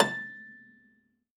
53p-pno17-A3.wav